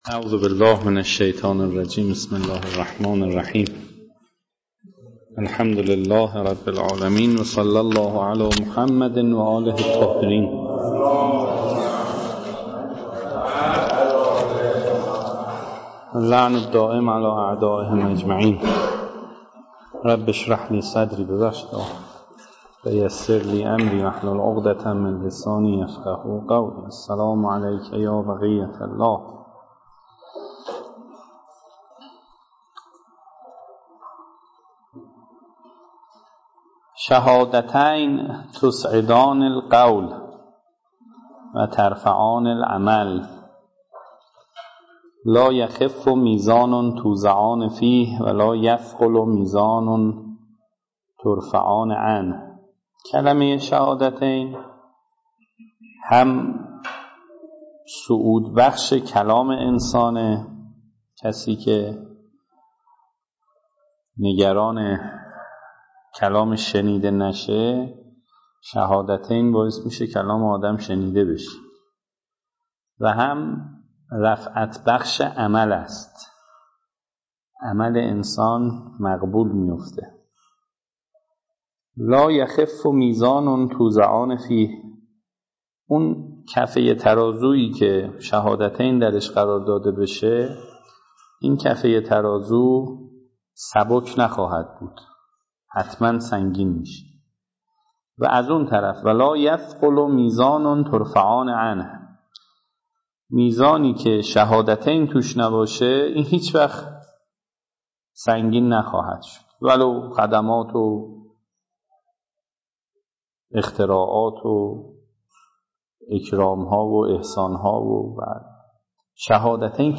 درس اخلاق مرکز علوم اسلامی امام خمینی(ره)